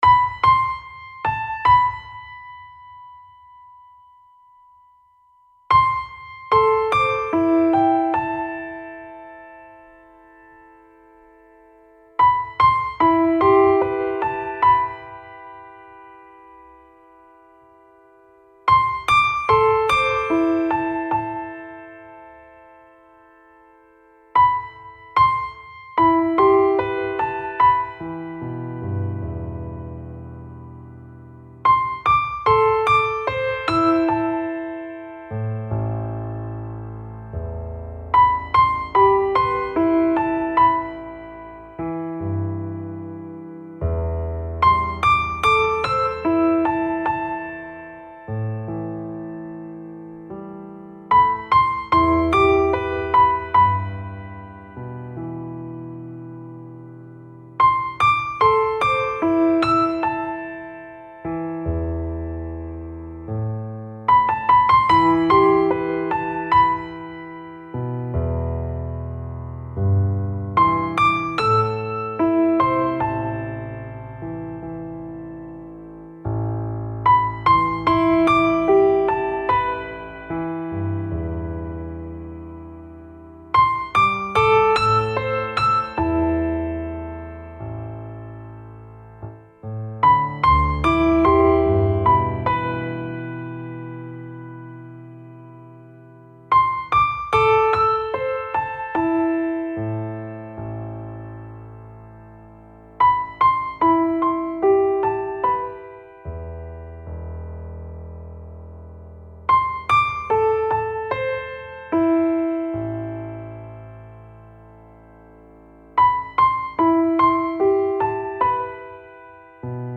Грустные мелодии для дошкольников